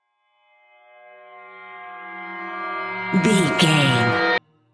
Synth Swell Lo
Sound Effects
In-crescendo
Atonal
ominous
eerie
synthesizer